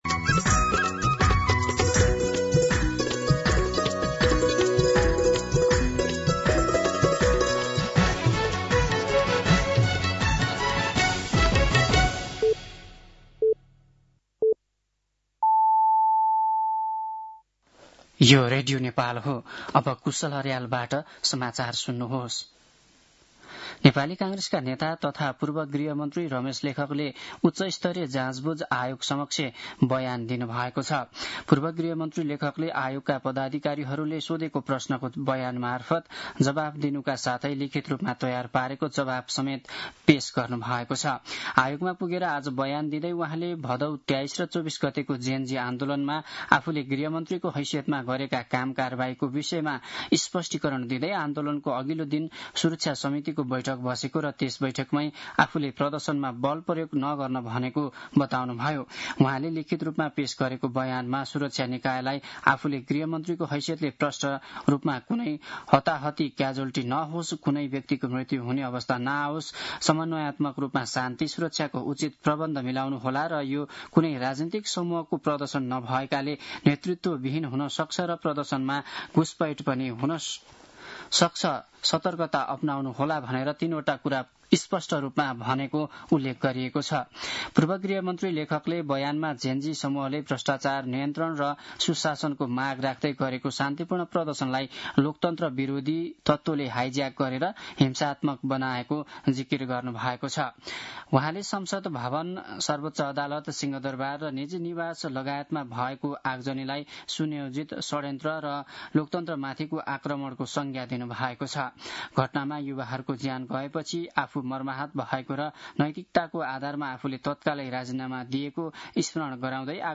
दिउँसो ४ बजेको नेपाली समाचार : १४ पुष , २०८२
4-pm-News-9-14.mp3